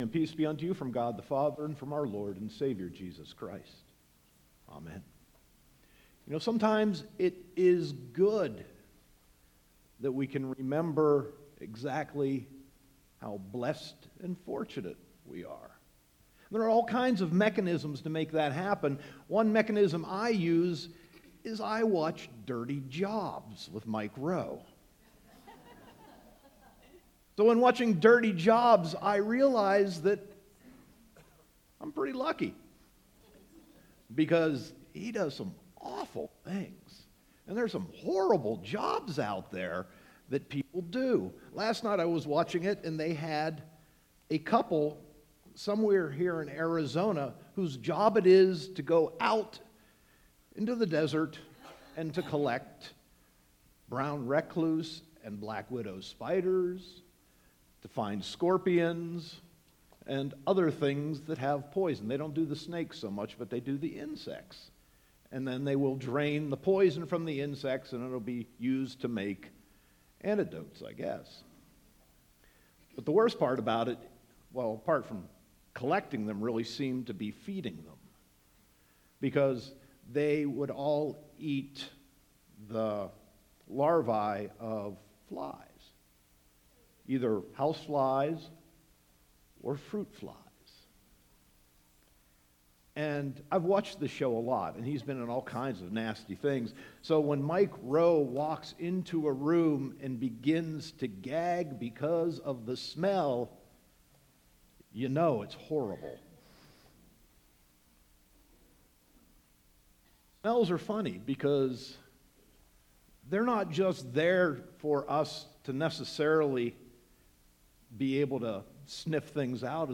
Sermon 3.18.2018